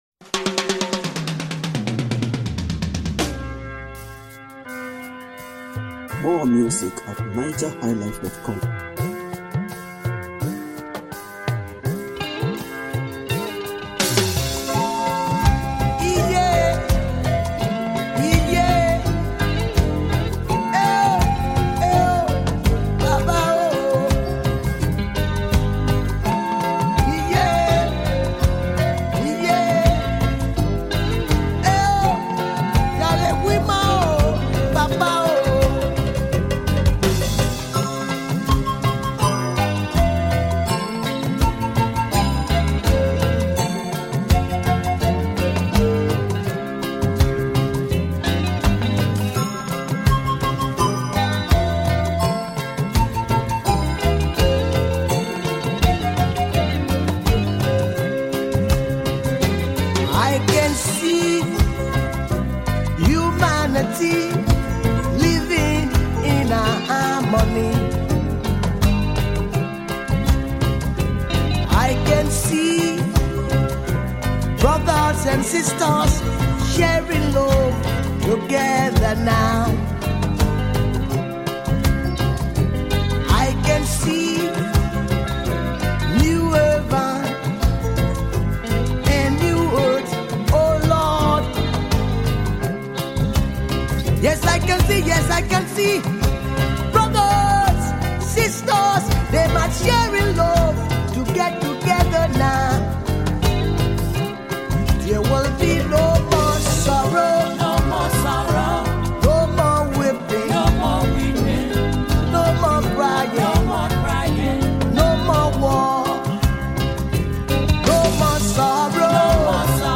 Home » Ragae
Wonderful Reggae Music
highly powerful Reggae Music